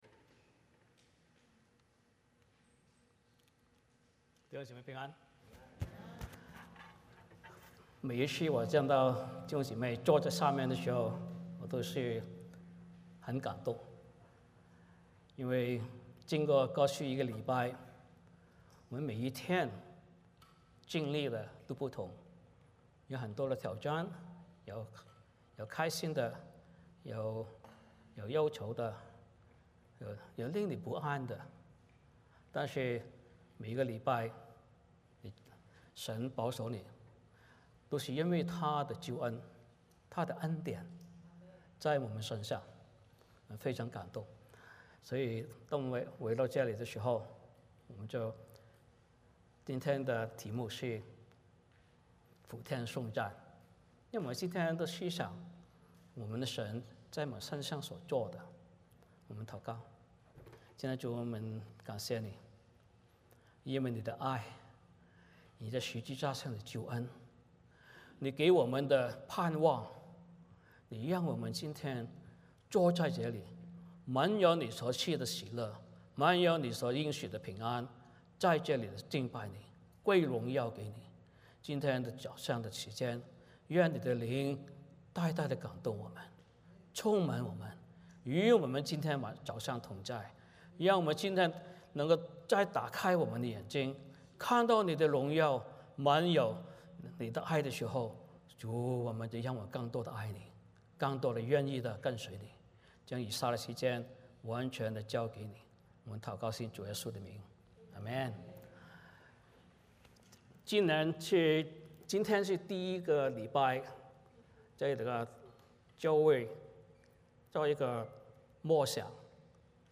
Passage: 詩篇100篇 Service Type: 主日崇拜 欢迎大家加入我们的敬拜。